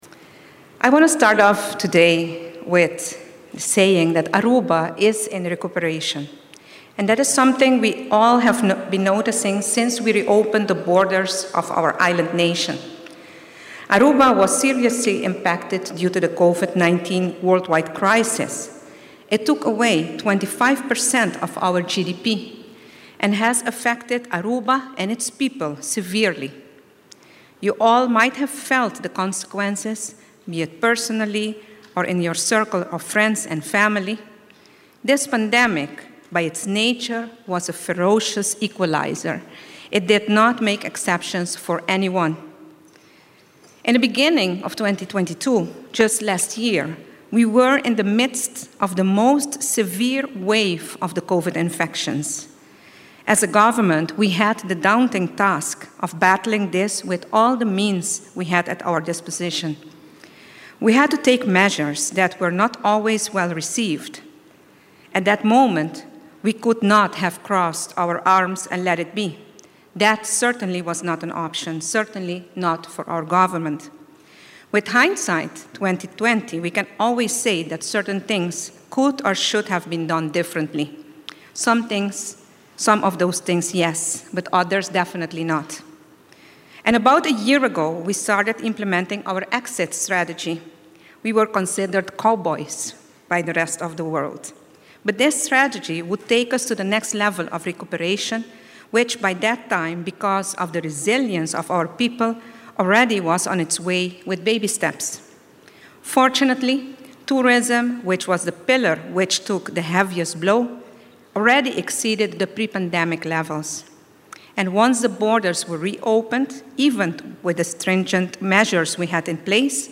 Prome Minister tabata presente pa atende evento organisa pa Aruba Ports Authority (APA), na unda nan a presenta oficialmente e proyecto di Port City na “lenders” y na diferente compania. Prome Minister, durante su discurso a splica tocante e impacto cu e pandemia tabata tin riba e economia di pais Aruba y e pasonan cu Gobierno mester a tuma e tempo ey pa salvaguardia bida di esnan severamente afecta. Prome Minister tambe a indica cu actualmente pais Aruba ta den recuperacion y cu inversionnan den proyectonan manera Port City ta di gran beneficio pa e economia di e pais.